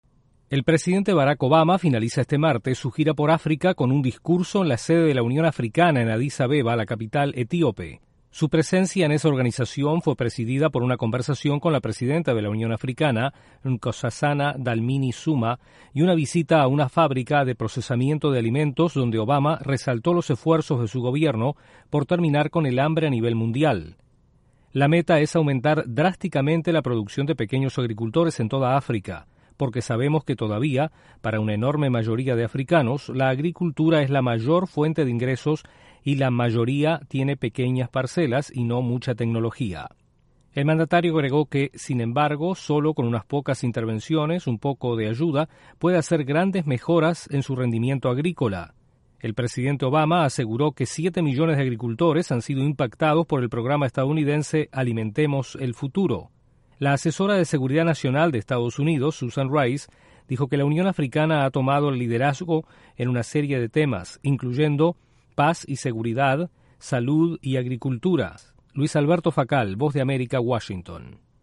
El presidente de EE.UU., Barack Obama, concluye su visita a África con un discurso ante la Unión Africana. Desde la Voz de América en Washington informa